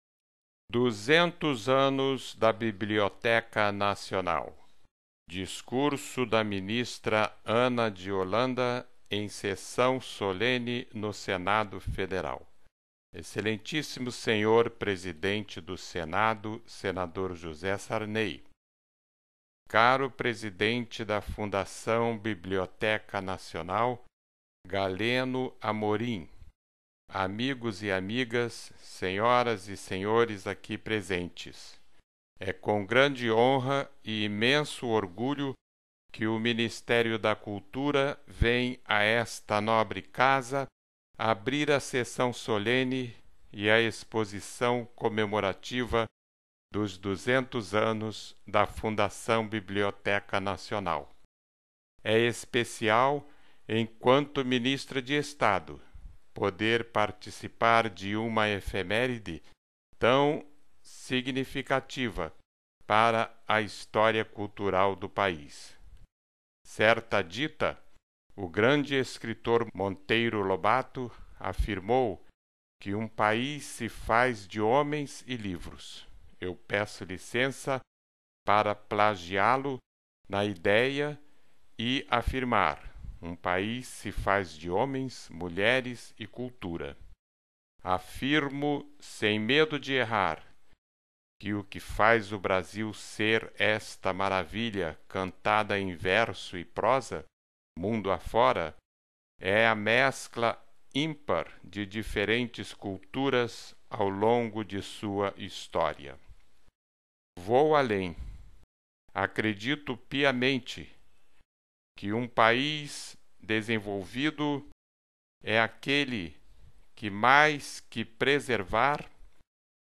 Os ditados desta página, que podemos chamar de "TAQUITESTES", foram selecionados para você testar a sua velocidade taquigráfica.
Taquitestes de 85 palavras por minuto.